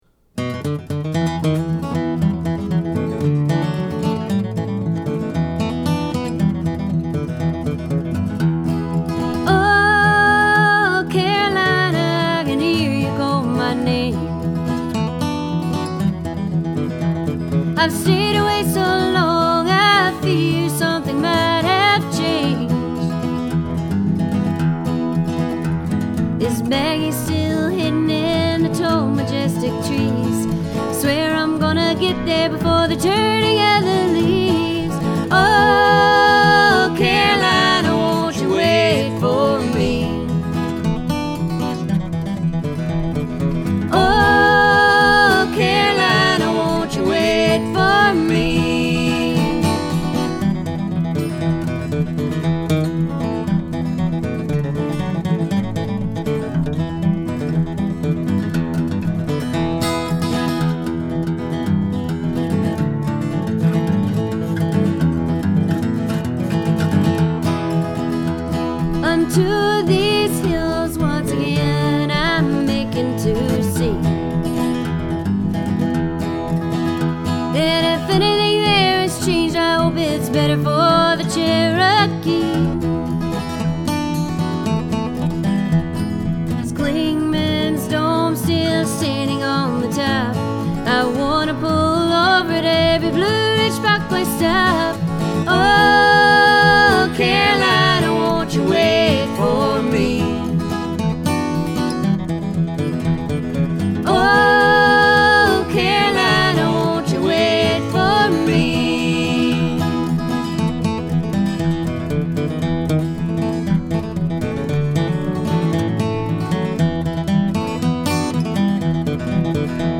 the sounds of the Blue Ridge Mountains